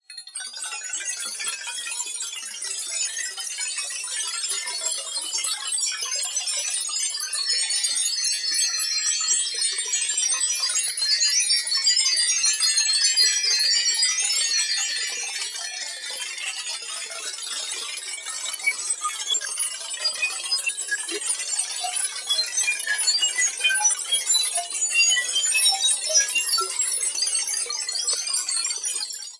描述：闪闪发光的金属颗粒，银色和闪闪发光的金色声音。通过计算机合成生成样本。
Tag: 风钟声 金属 金属 摇铃 波光粼粼 合成